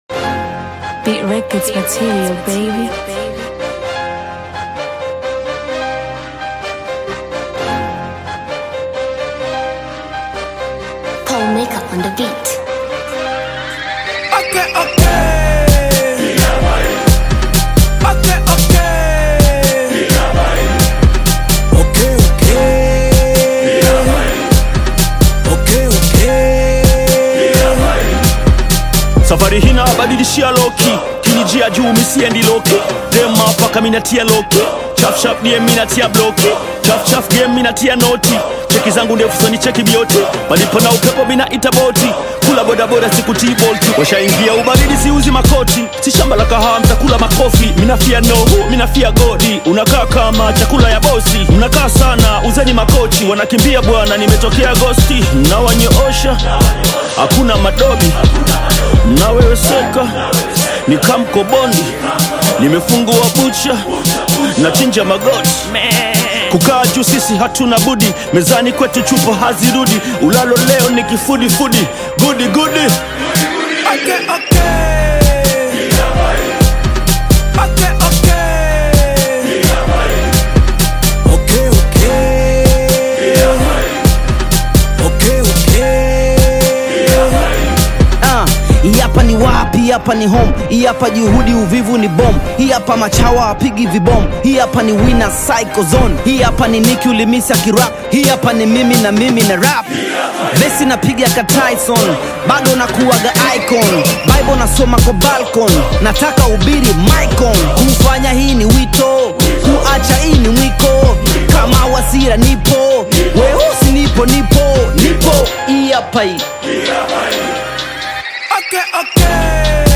vibrant and energetic track
Tanzanian hip-hop group